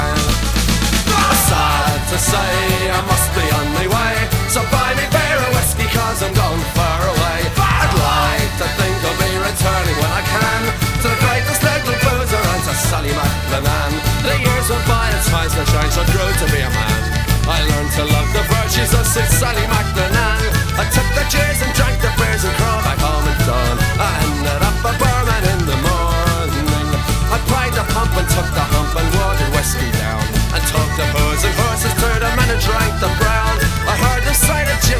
Celtic Singer Songwriter Traditional Folk Rock
Жанр: Поп музыка / Рок / Альтернатива / Фолк